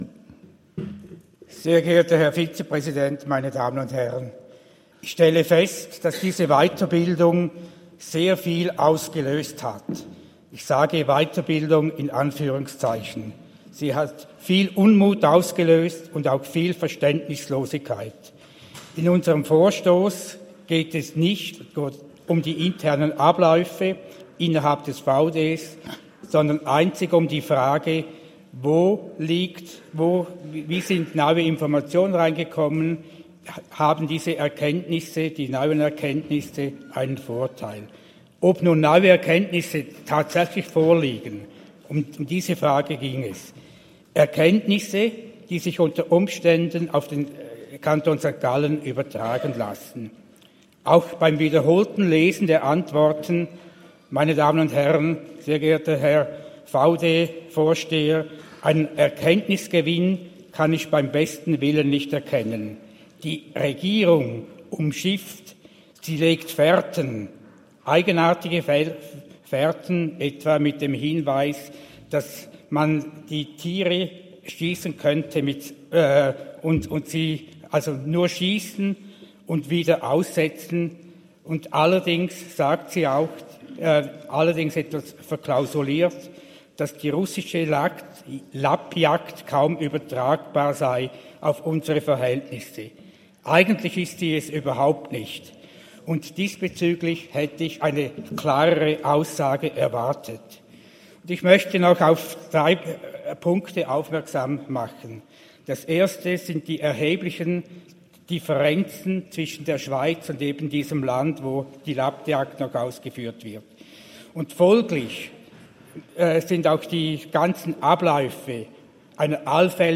18.9.2024Wortmeldung
Session des Kantonsrates vom 16. bis 18. September 2024, Herbstsession